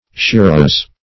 Shiraz \Shi*raz"\, n. A kind of Persian wine; -- so called from the place whence it is brought.